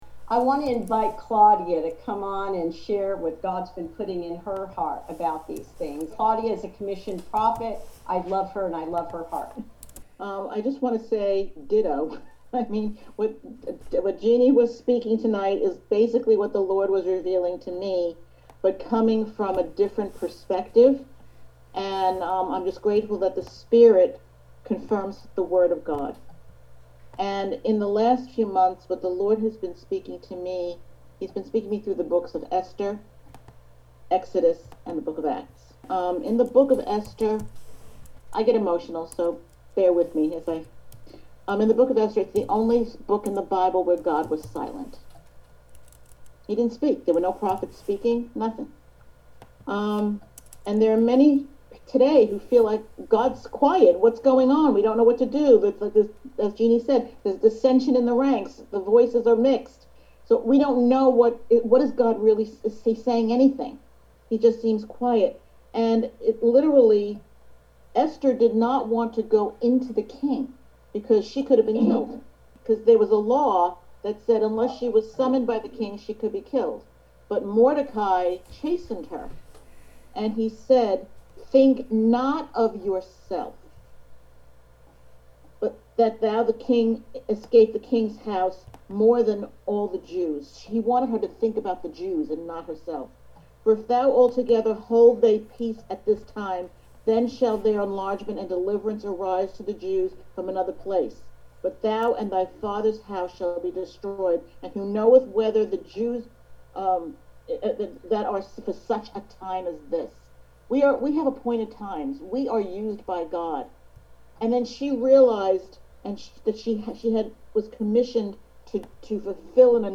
Guest presenter